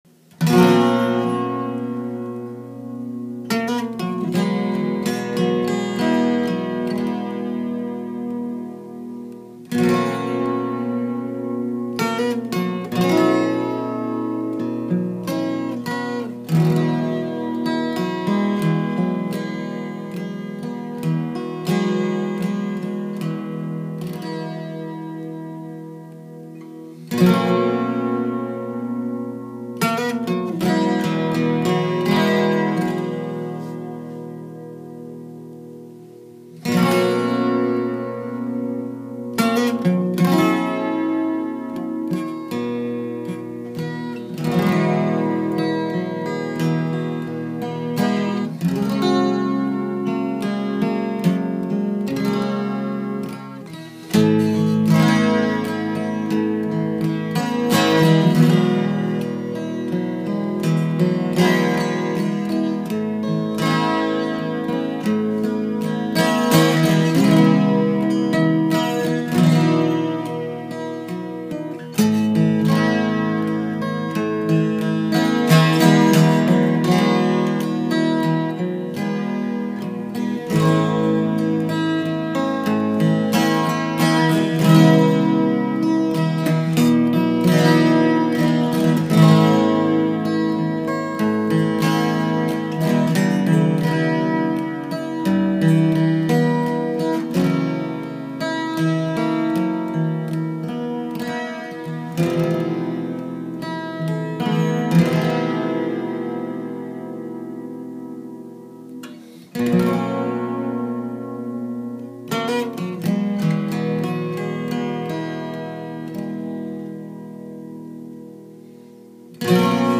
Kindness, Patience, Forgiveness (Instrumental, 2013)
Martin 12-string guitar